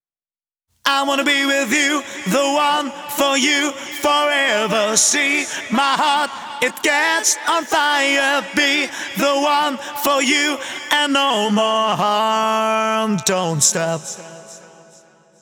Vocal-Hook Kits